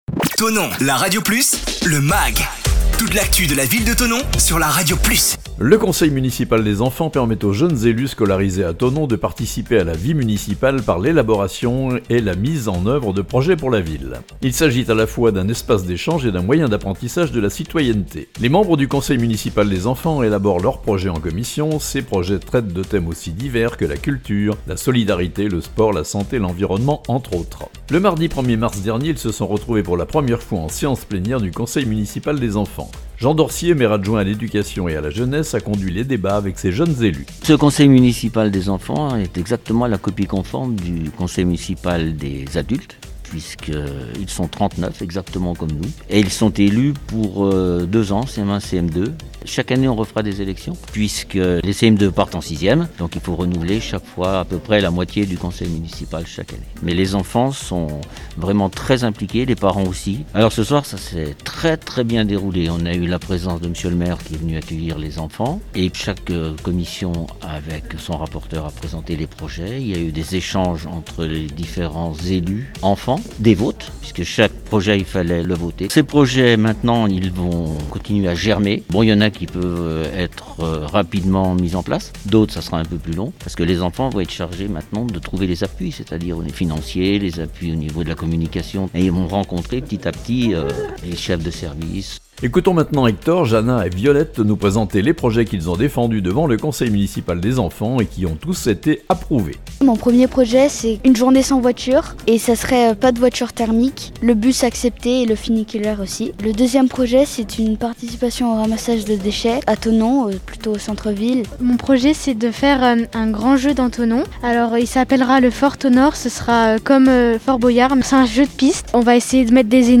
Le premier conseil municipal des enfants vient d'avoir lieu. Reportage.